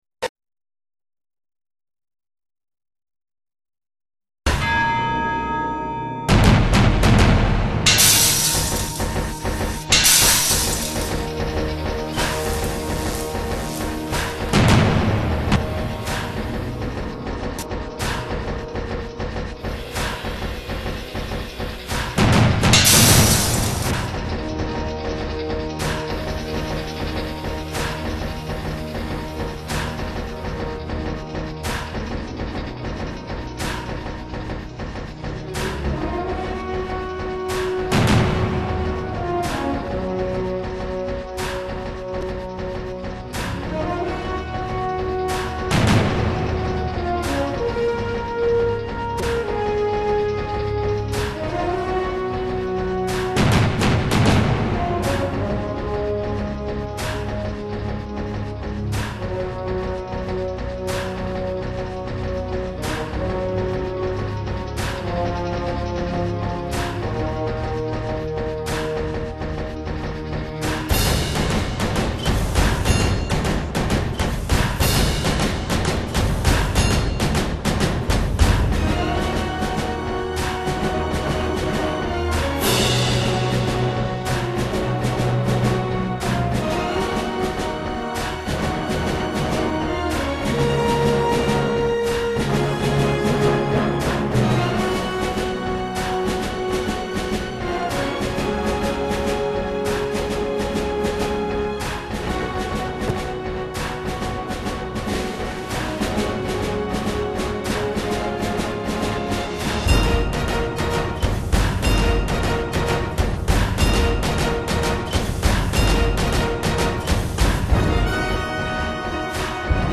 无与伦比的录音，请注意音量